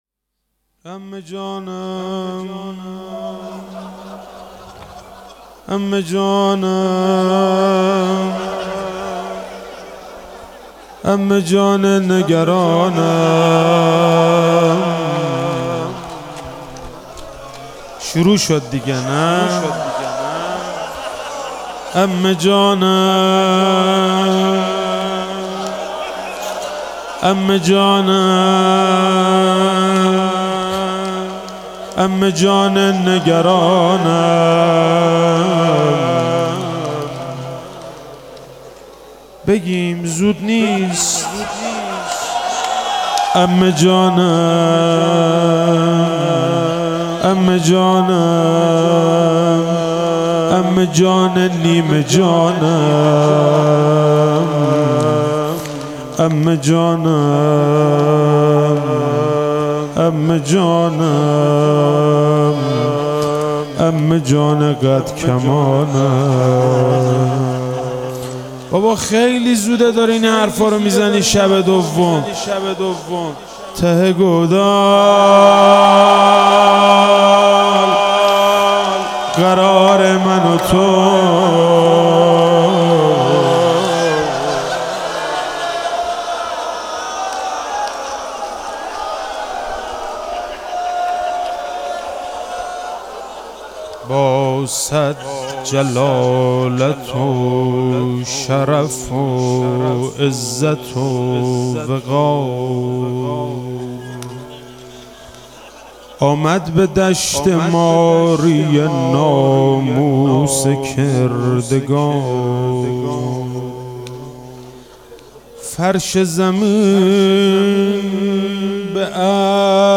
شب دوم محرم 97 - روضه - عمه جانم عمه جانم عمه جا نگرانم